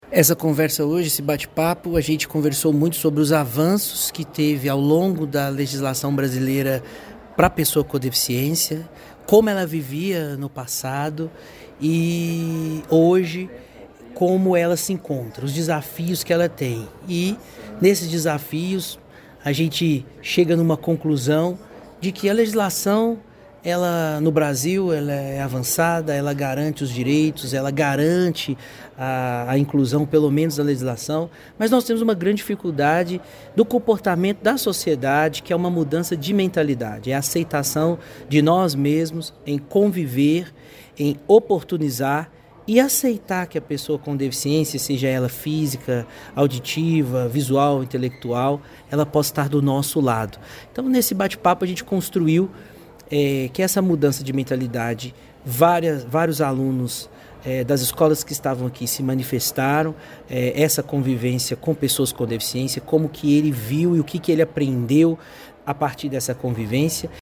Palestra reforça reflexão sobre igualdade e respeito
Durante a conversa com estudantes e participantes do evento, ele abordou os avanços da legislação brasileira voltada para as pessoas com deficiência e destacou que, apesar das conquistas legais, ainda existe um grande desafio relacionado à mudança de mentalidade da sociedade: